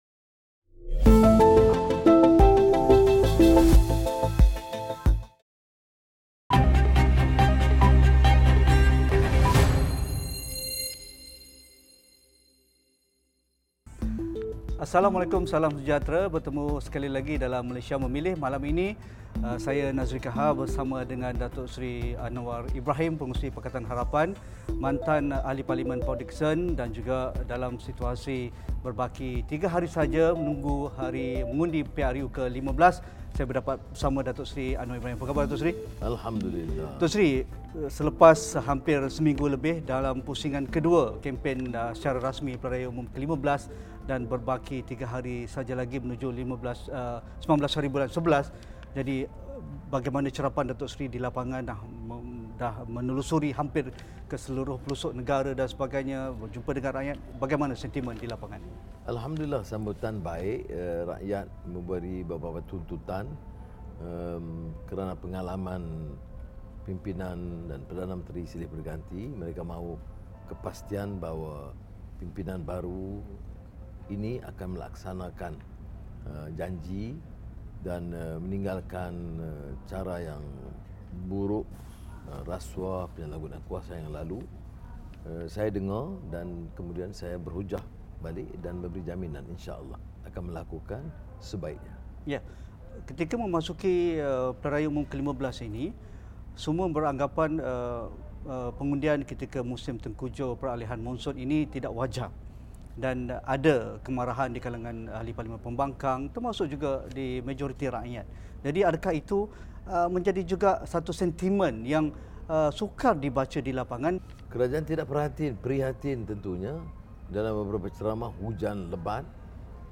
Mampukah Datuk Seri Anwar Ibrahim bawa PH kembali ke Putrajaya dan angkatnya sebagai Perdana Menteri seterusnya pada PRU15? Temu bual khas bersama Pengerusi PH.